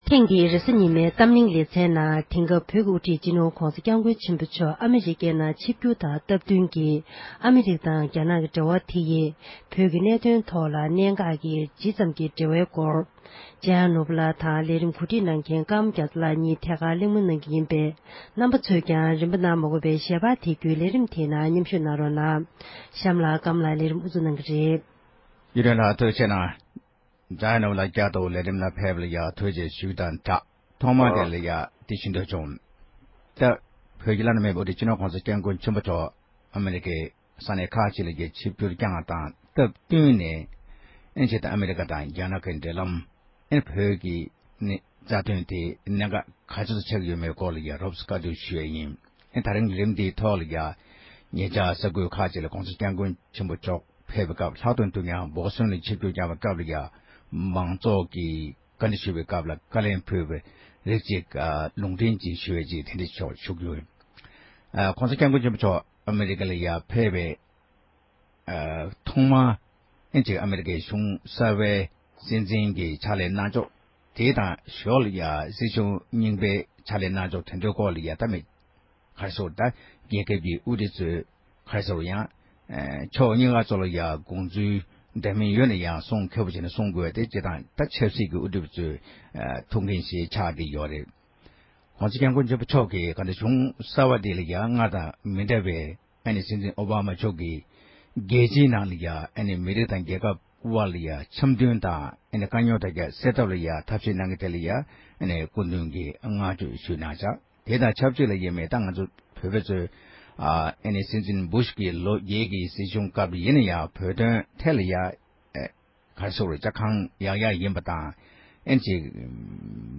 ཨ་མེ་རི་ཀ་དང་རྒྱ་ནག་གི་འབྲེལ་ལམ་དབར་བོད་ཀྱི་གནད་འགག་སྐོར་གྱི་བགྲོ་གླེང་།